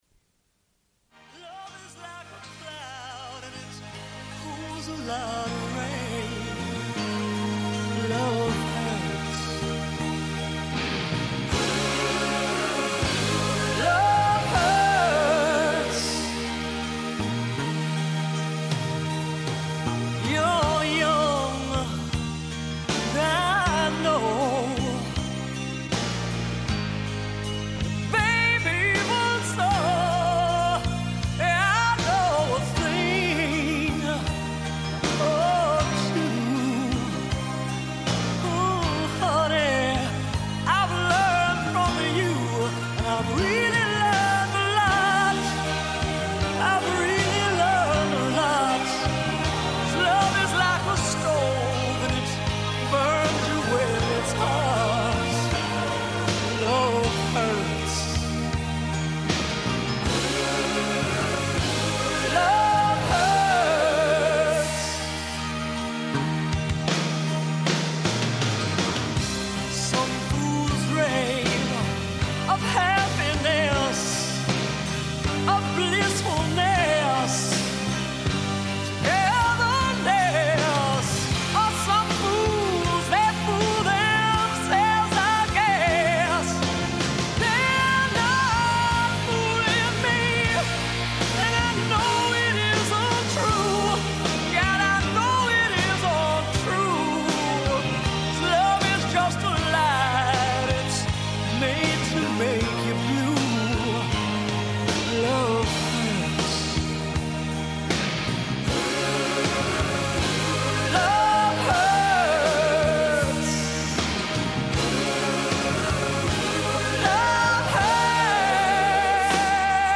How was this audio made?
Une cassette audio, face A31:49